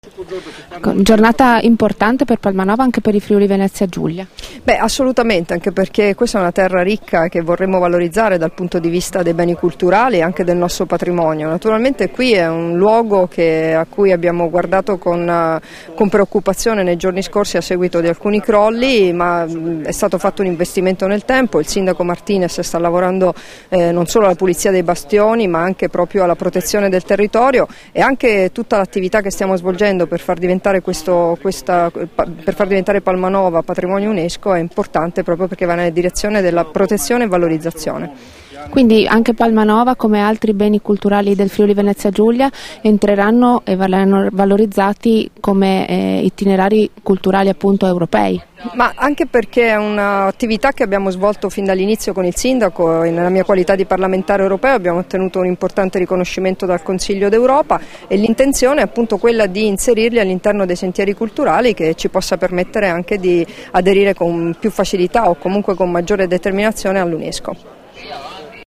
Dichiarazioni di Debora Serracchiani (Formato MP3) [1099KB]
rilasciate in occasione della pulizia dei bastioni a cura dei volontari di Protezione civile, a Palmanova l'8 marzo 2014